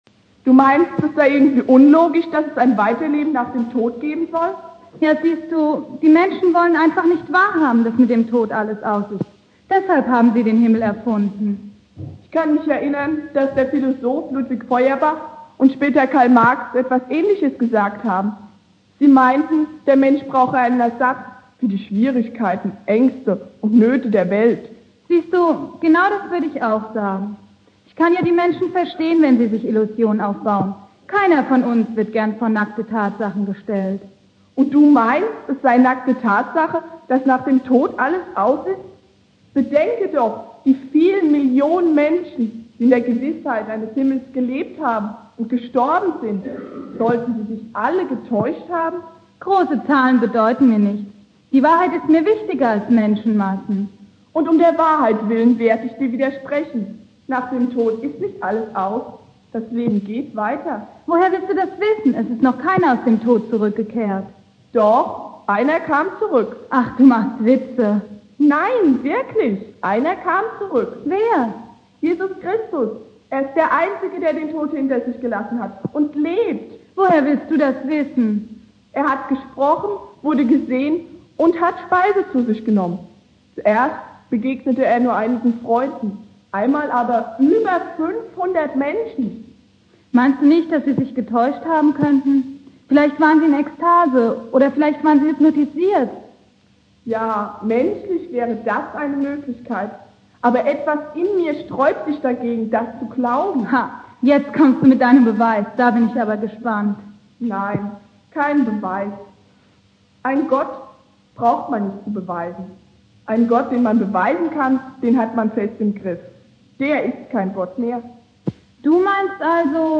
Predigt
Thema: "Leben nach dem Tod" (Anspiel und Predigt) Predigtreihe